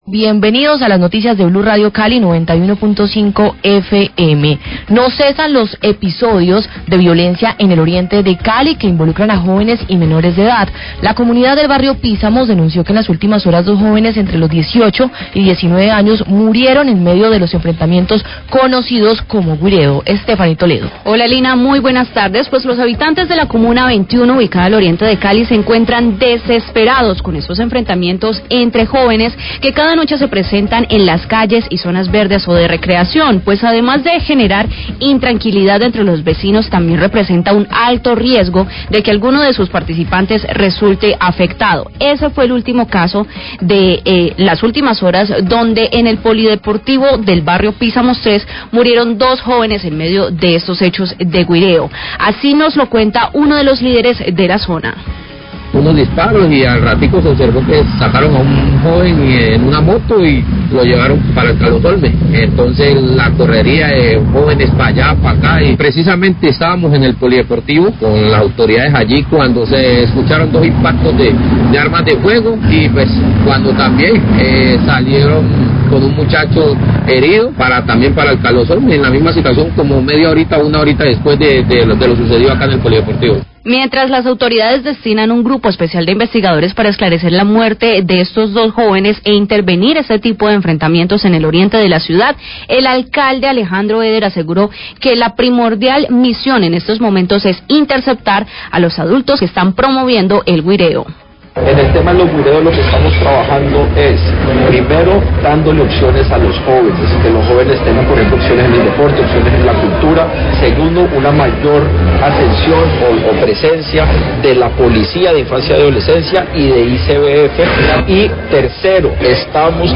Radio
La comunidad del barrio Pizamos III denuncian que dos jóvenes murieron en los enfrentamientos de anoche conocidos como "guireo". El alcalde de Cali, Alejandro Eder, explica las medidas de control que emprenderá su administración para evitar este tipo de enfrentamientos callejeros.